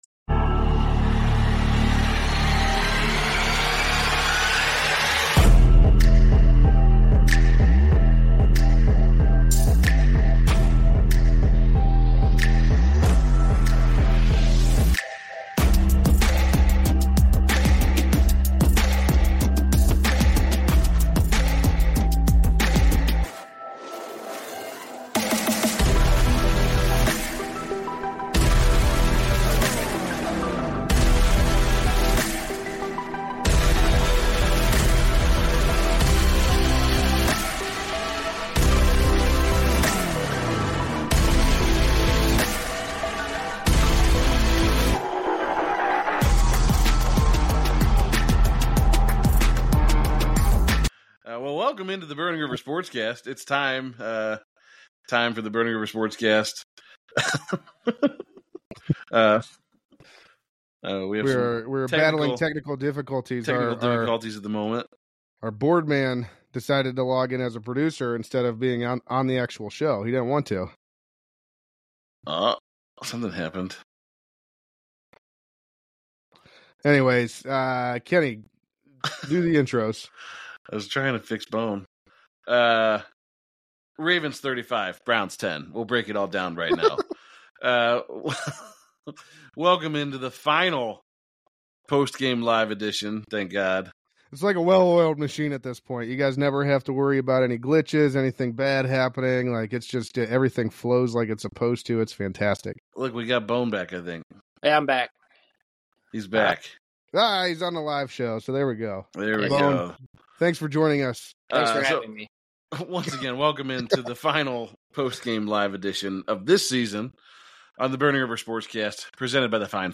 It's time! Time for Burning River Sportscast presented by Tap In Media to go live again!